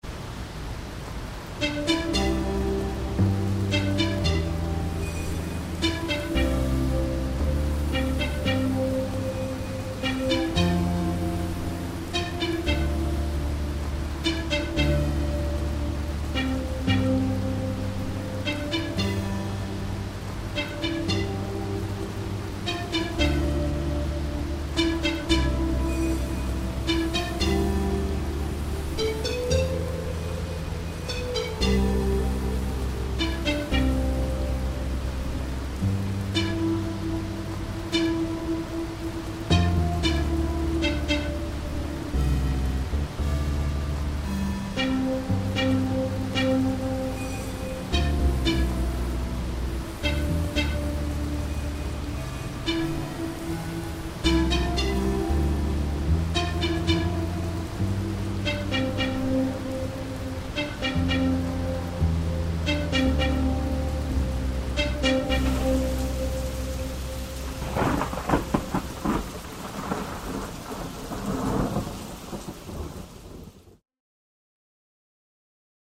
heavy-rainYS.mp3